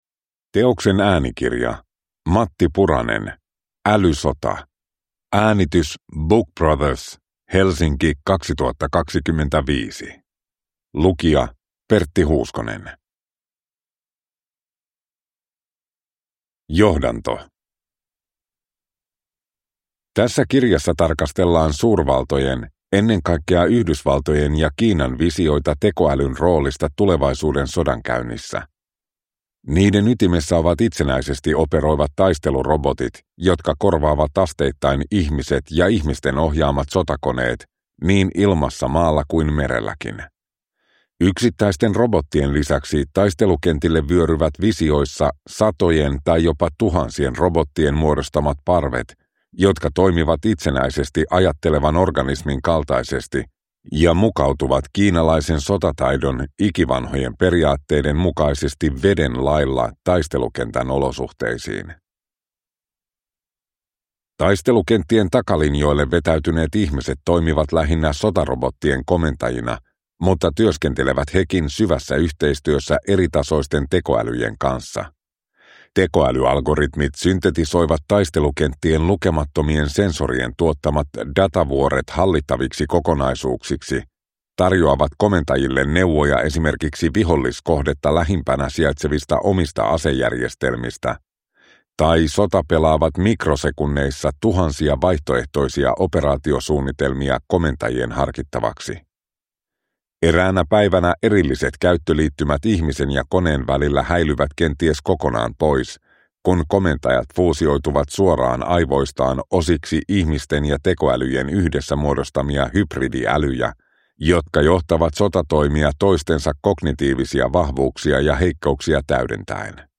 Älysota – Ljudbok